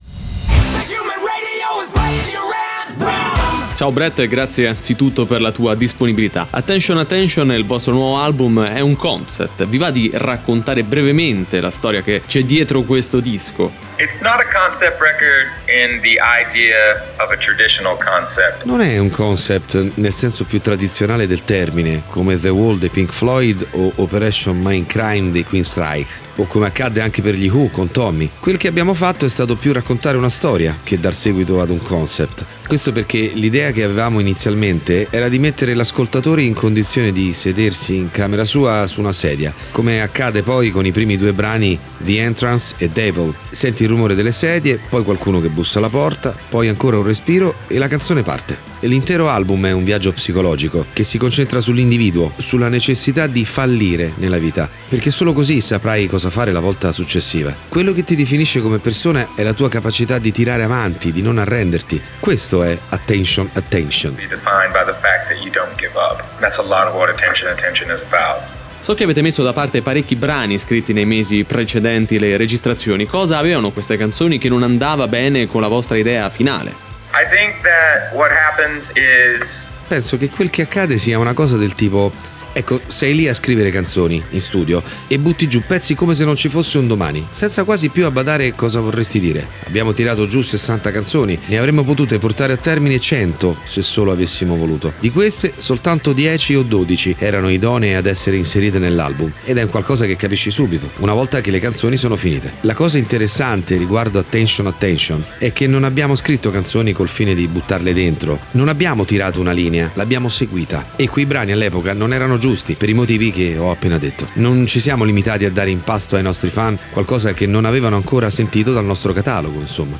Intervista: Brent Smith - Shinedown (21-07-18)
Brent Smith degli Shinedown in collegamento telefonico per parlare del nuovo album "Attention Attention", uscito a maggio, e molto altro ancora.